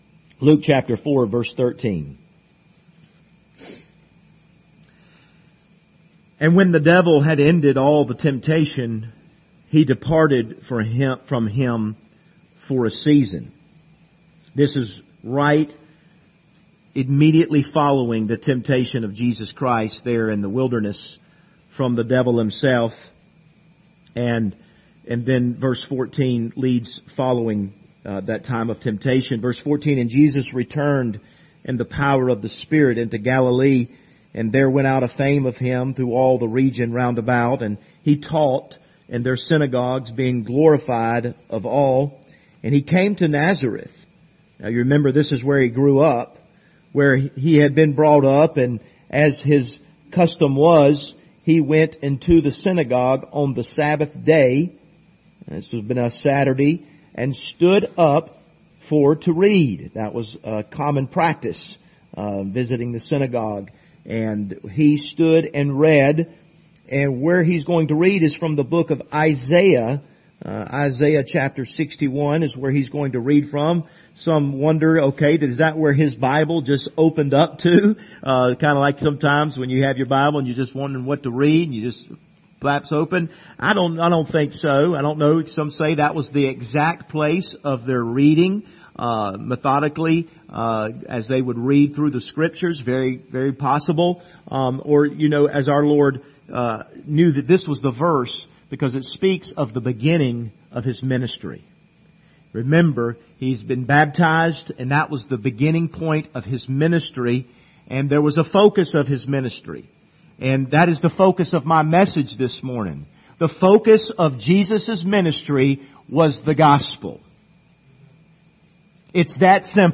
Passage: Luke 4:13-18, John 3:10-16 Service Type: Sunday Morning